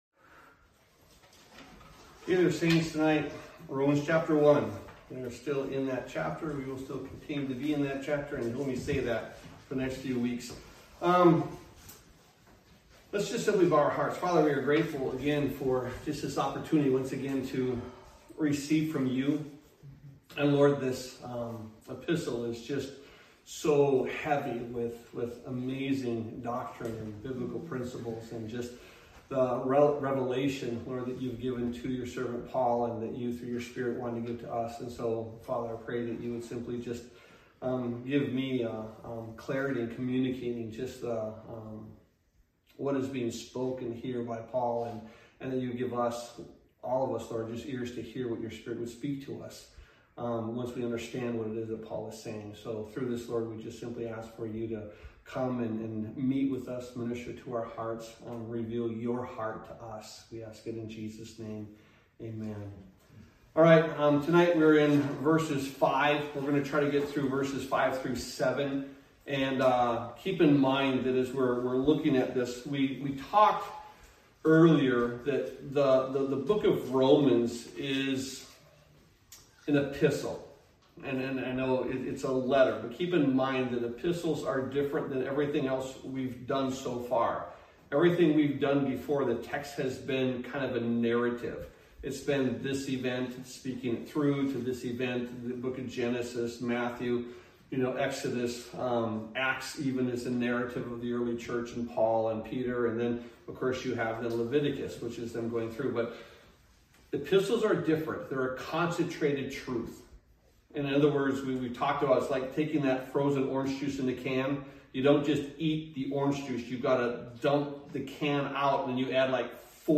Sermons | Calvary Chapel Milwaukee